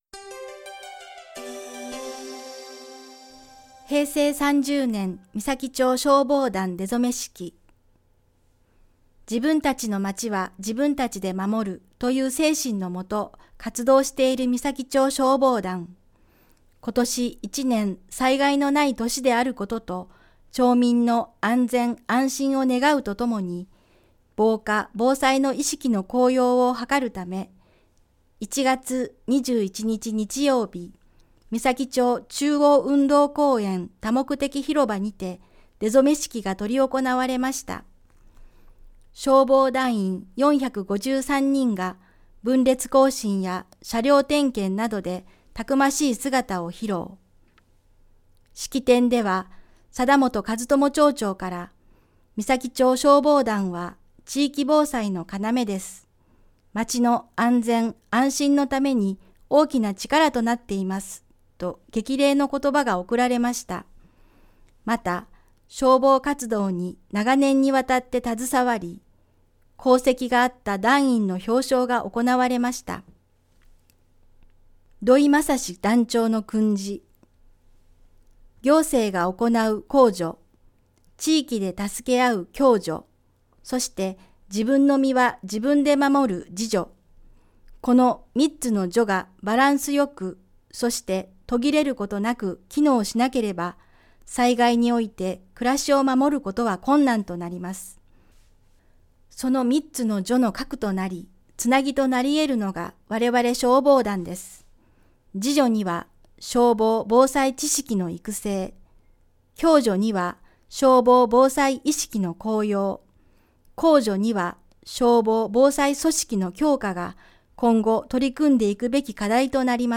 広報誌の一部を読み上げています。